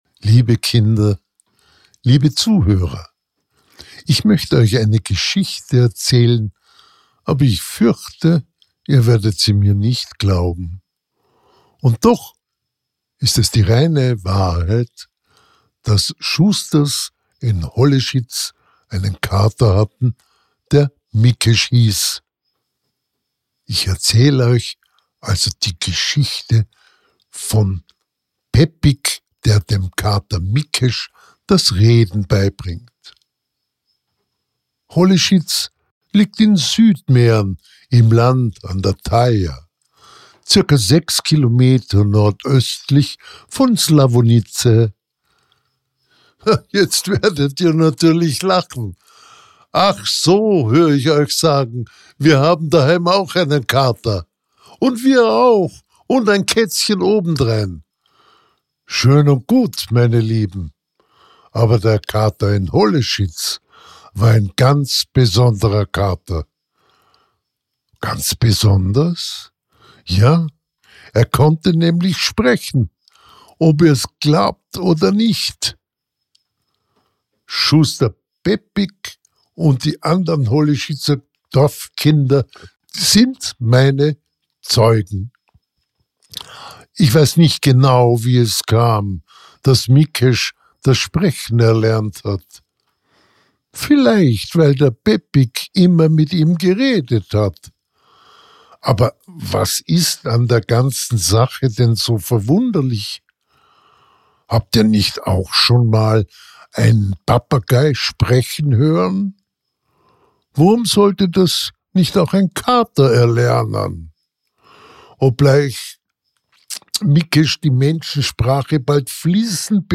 Zusammen mit seinen Freunden, dem Schwein Paschik und dem Ziegenbock Bobesch, heckt er allerhand Blödsinn aus. Ich erzähle einige Geschichten vom tschechischen Autor Josef LADA und den wunderbaren Abenteuern dieser charmanten Mitbewohnern aus Nordböhmen.